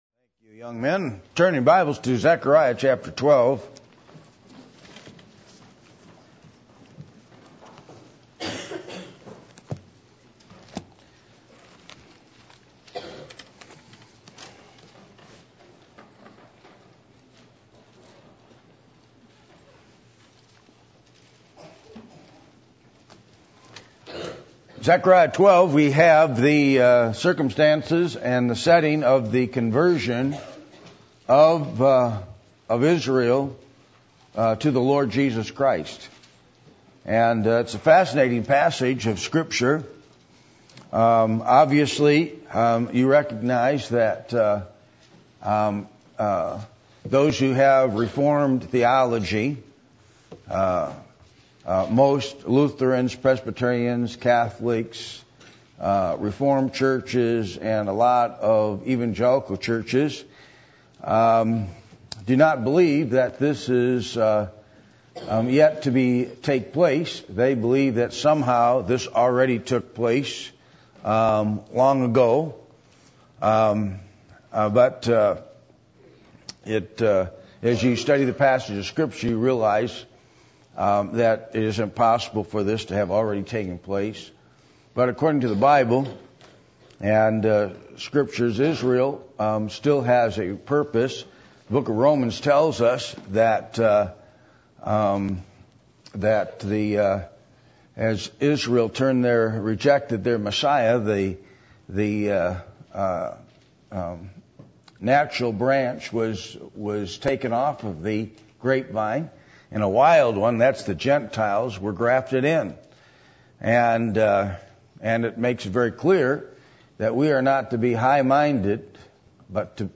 Passage: Zechariah 12:1-14 Service Type: Sunday Evening